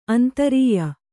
♪ antarīya